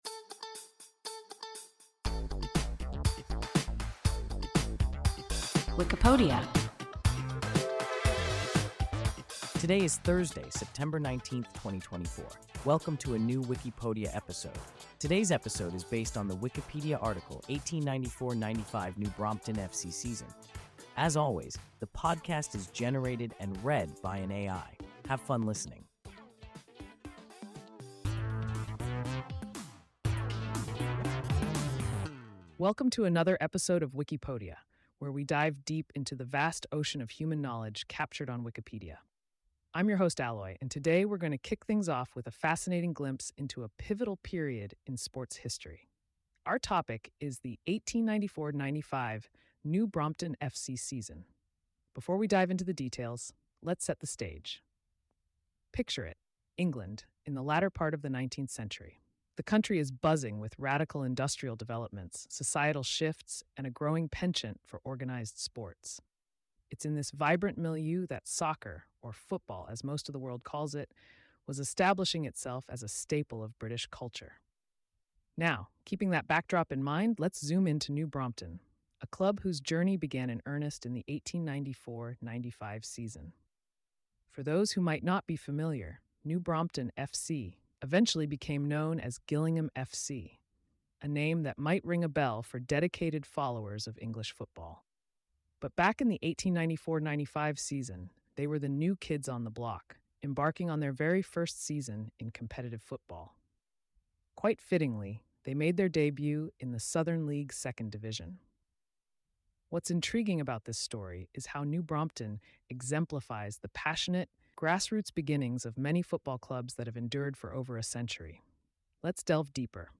1894–95 New Brompton F.C. season – WIKIPODIA – ein KI Podcast